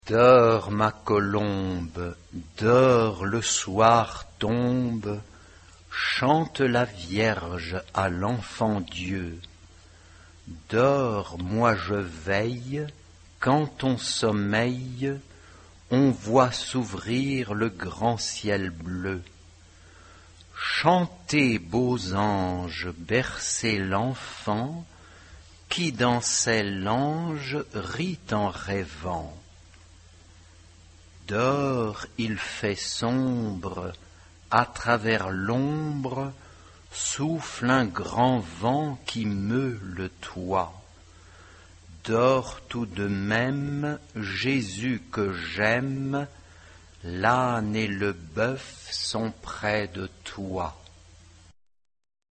SATB (4 voix mixtes) ; Partition complète.
Chant de Noël. noël.
Type de choeur : SATB (4 voix mixtes )
Tonalité : ré majeur